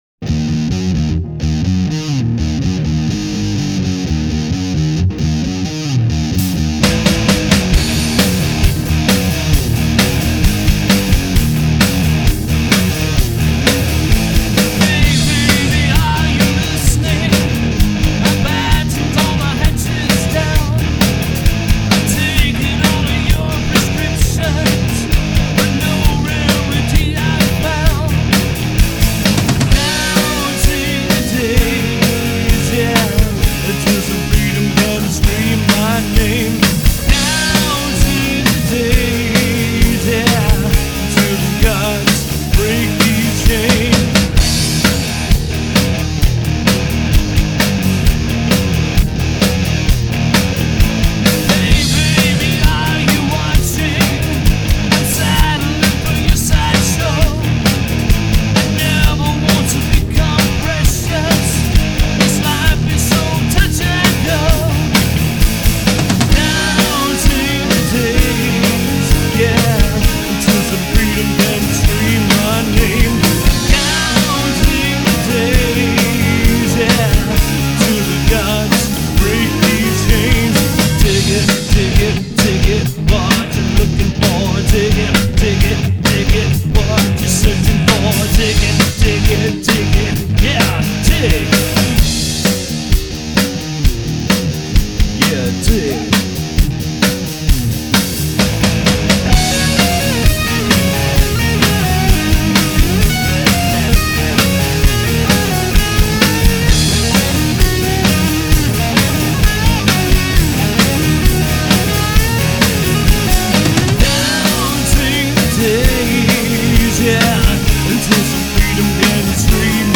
vocals & percussions
guitar & vocals
bass
drums
We love to play powerful and with variatons of dynamics.
The bass and the drums are tight and powerful